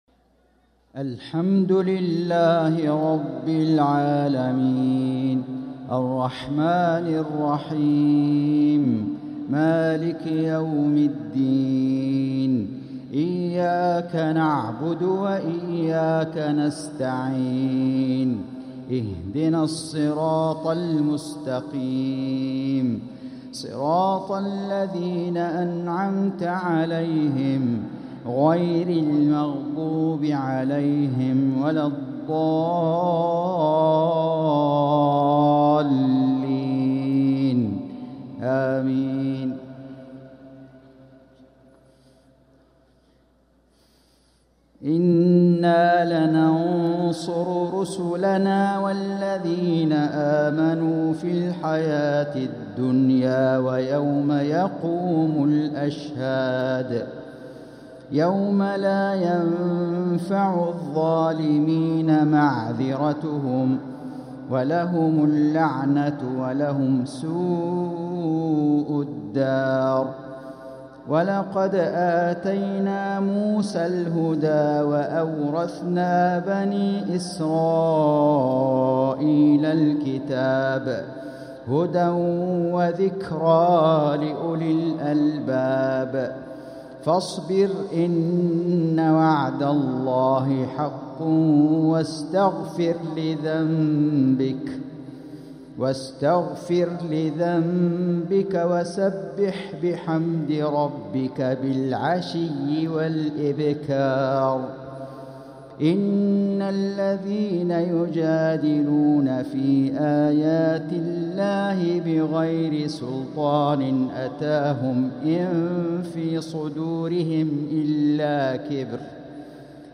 صلاة المغرب ٨-٧-١٤٤٦هـ | من سورة غافر 51-60 | Maghrib prayer from Surah Ghafir | 8-1-2025 🎙 > 1446 🕋 > الفروض - تلاوات الحرمين